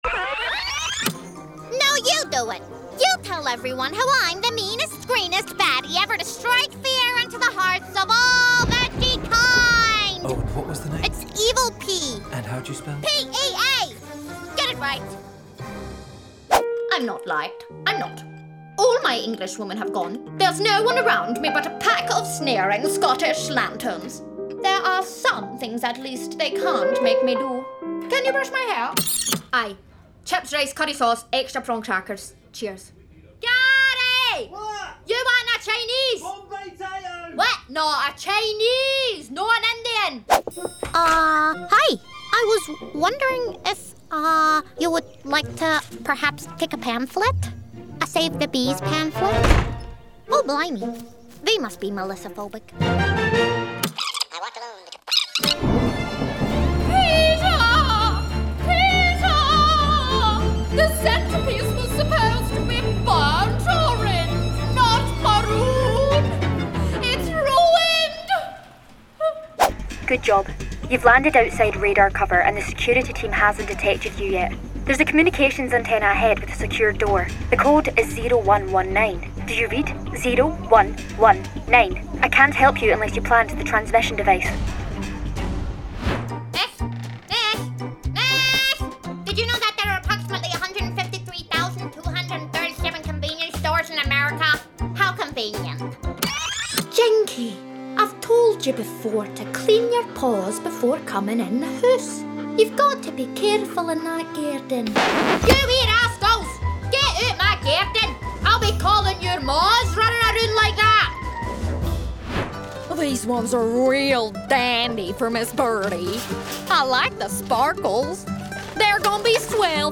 Young Adult, Adult
Has Own Studio
british rp | character
british rp | natural
scottish | character
scottish | natural
standard us | character
ANIMATION 🎬
COMMERCIAL 💸